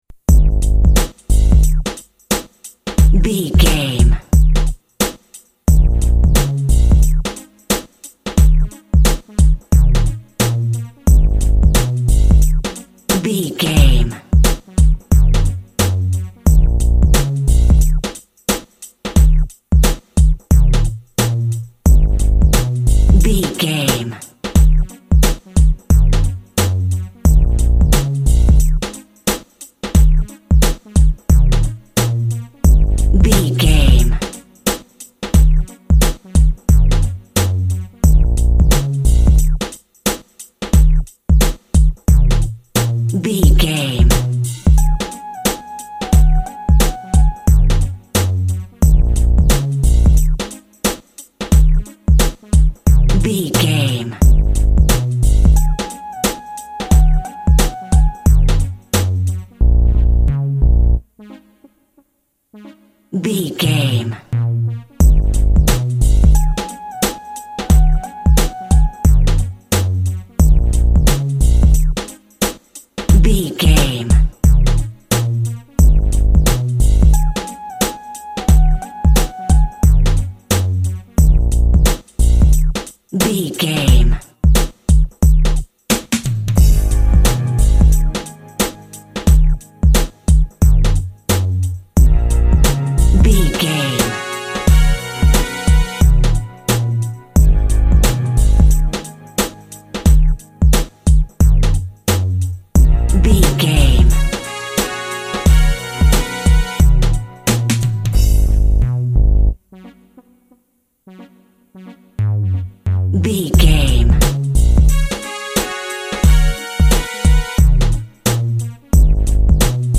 Aeolian/Minor
G#
hip hop
hip hop music
synth lead
synth bass
hip hop synths
electronics